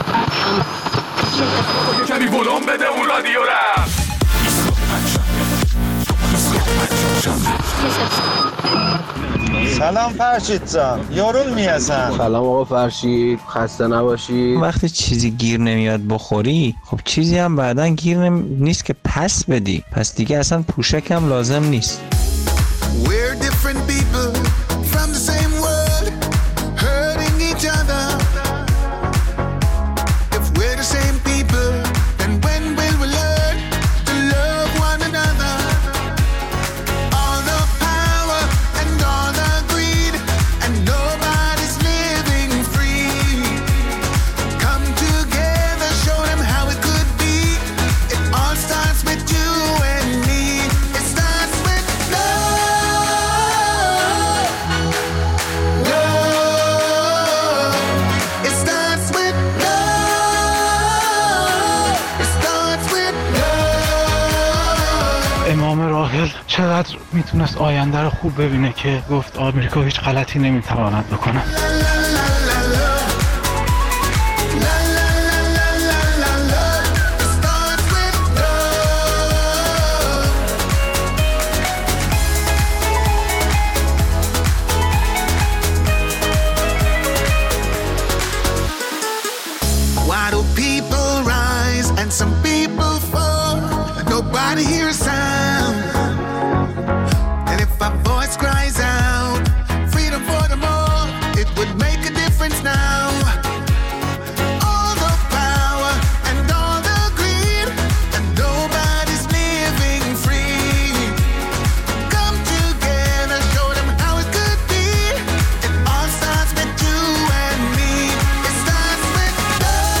در این برنامه ادامه نظرات شما را در مورد پدیده «کلی‌خری» بعضی از مردم و هجوم بعضی از هموطنان به فروشگا‌ه‌ها برای خریدن اقلام مختلف می‌شنویم.